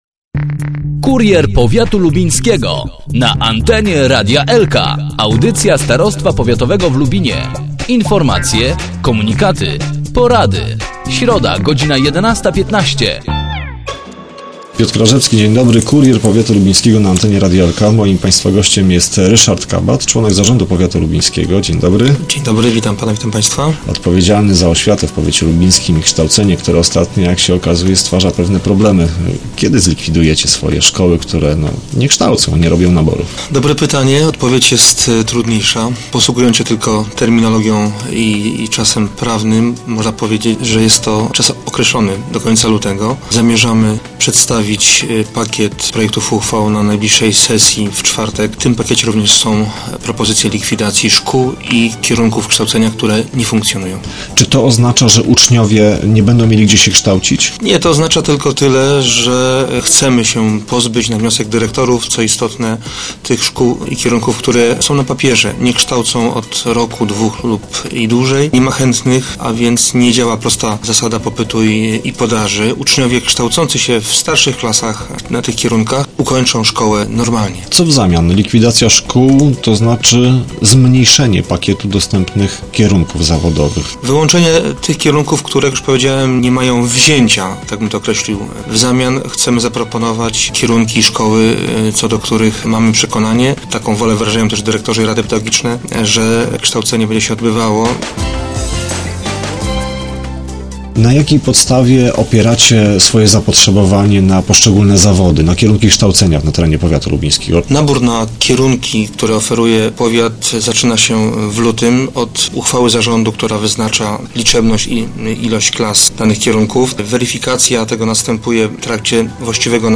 O szczegółach rozwoju zawodowego w powiecie z Ryszardem Kabatem, członkiem zarządu powiatu lubińskiego na antenie Radia Elka